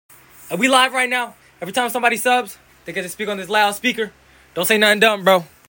SUB = TEXT TO SPEEK sound effects free download